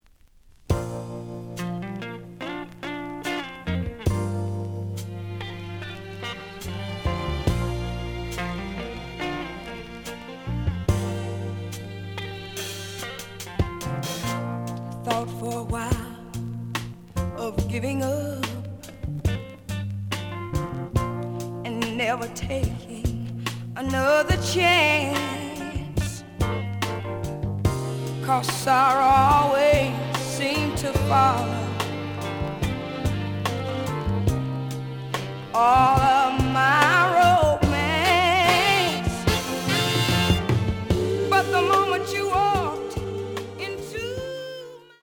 The audio sample is recorded from the actual item.
●Genre: Soul, 70's Soul
Slight cloudy on both sides. Plays good.)